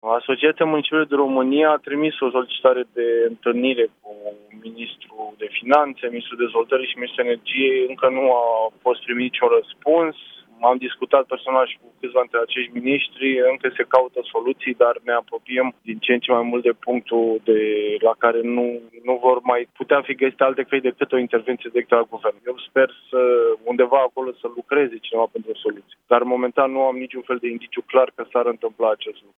Edilul – Lucian Viziteu pentru Europa Fm: